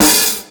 • Vinyl Bright Jazz Snare Sound B Key 30.wav
Royality free steel snare drum sound tuned to the B note. Loudest frequency: 6844Hz
vinyl-bright-jazz-snare-sound-b-key-30-gTh.wav